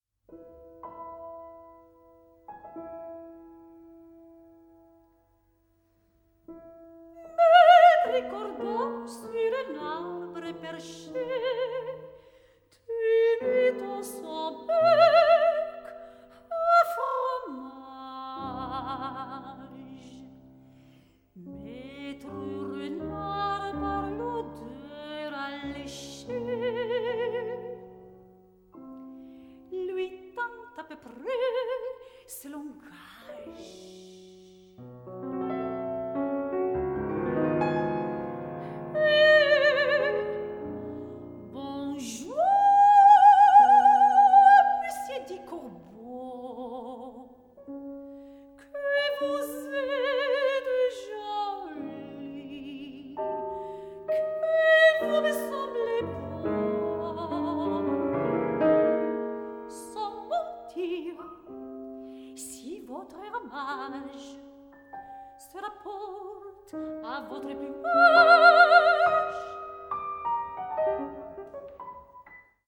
requiring absolute partnership between voice and piano.
VOCAL MUSIC
PIANO MUSIC